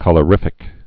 (kŭlə-rĭfĭk)